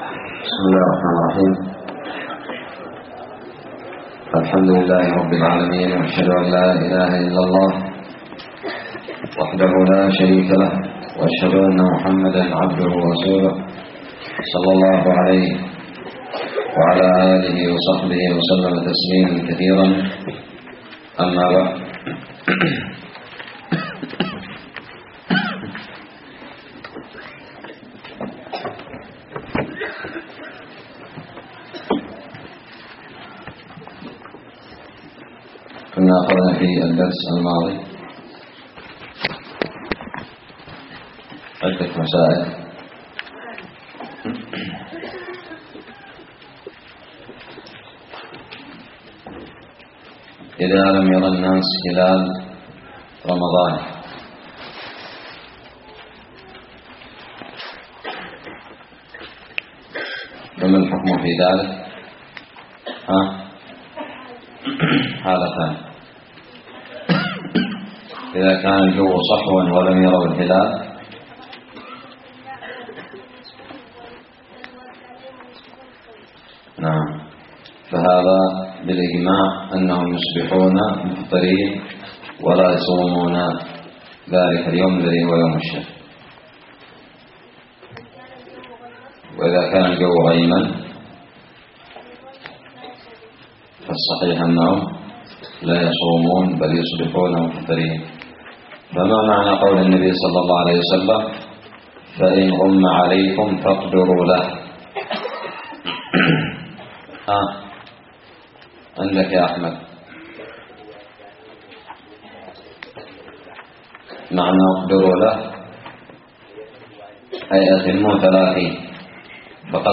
الدرس السابع من كتاب الصيام من الدراري
ألقيت بدار الحديث السلفية للعلوم الشرعية بالضالع